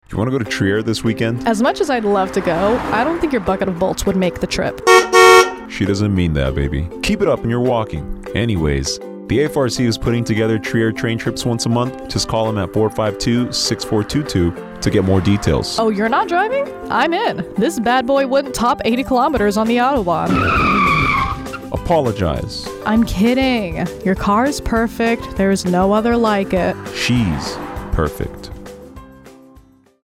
30 second radio spot for ITT's Trier Train Trips.